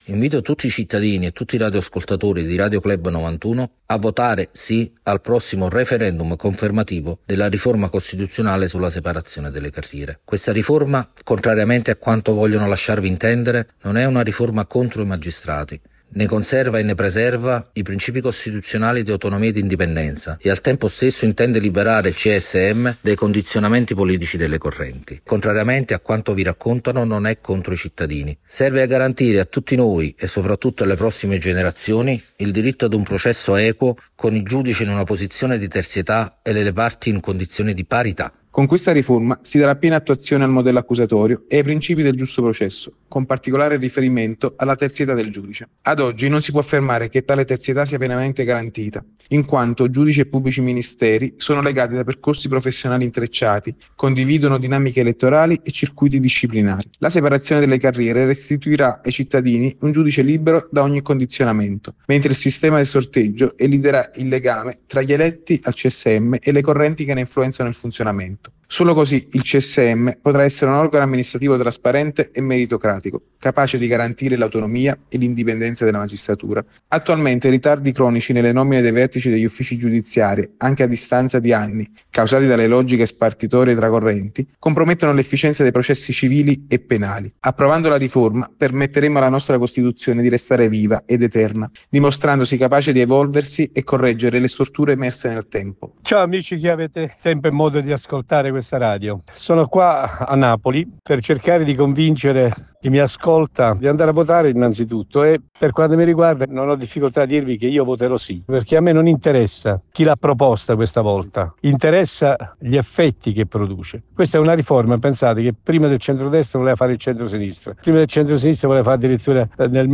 Per le ragioni del Sì ai nostri microfoni l’Avv.